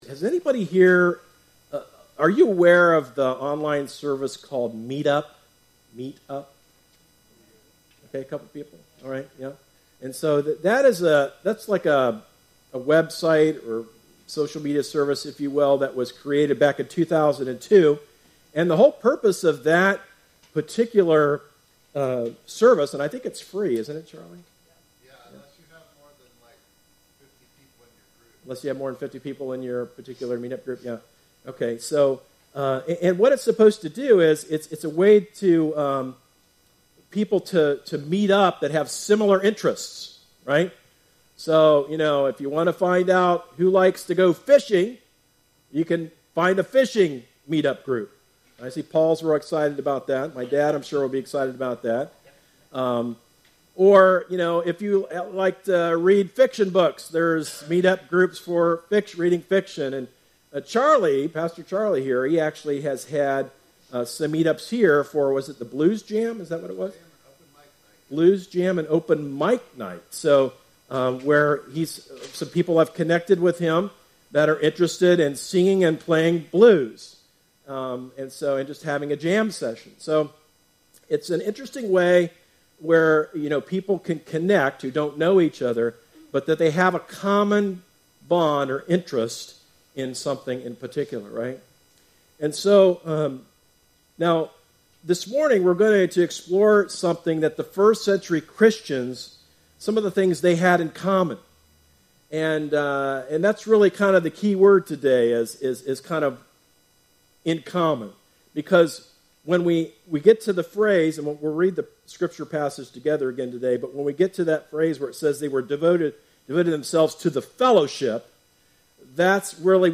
A message from the series "Devoted." The first century church was known to be a church that was devoted to prayer.